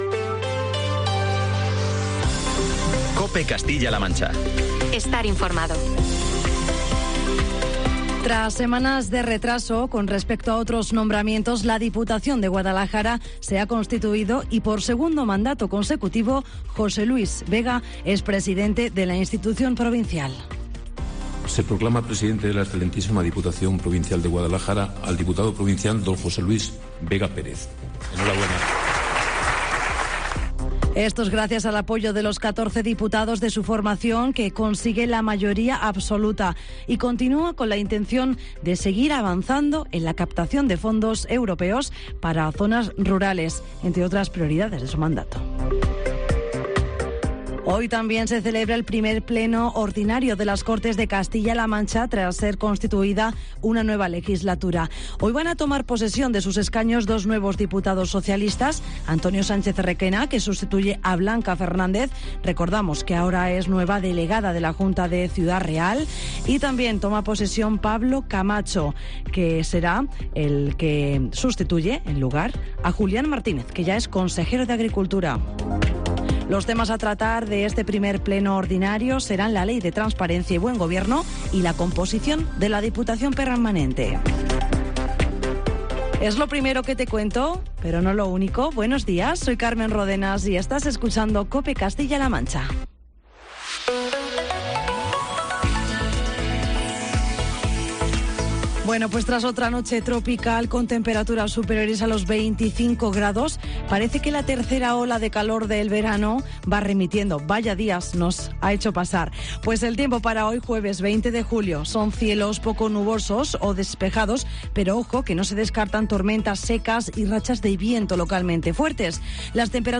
Castilla-La Mancha INFORMATIVO Herrera en COPE Castilla- La Mancha a las 07:20 - 20 de Julio.